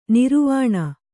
♪ niruvāṇa